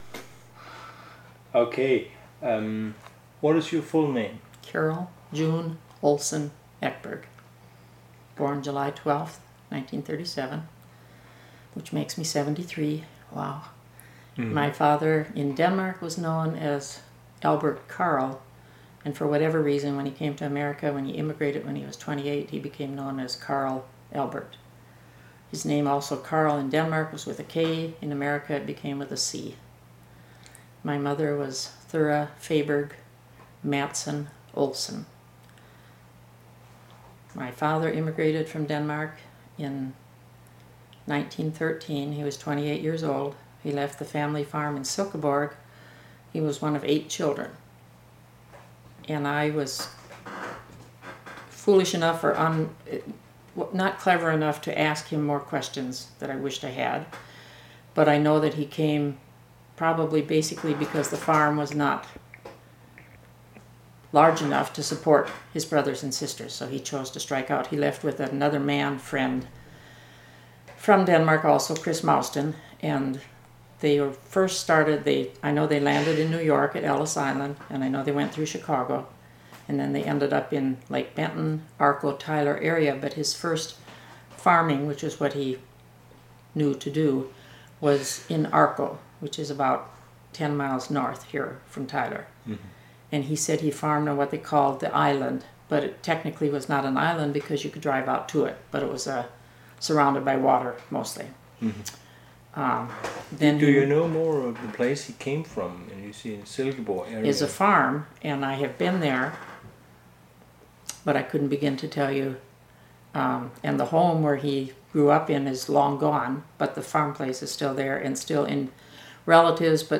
Audio recording of the interview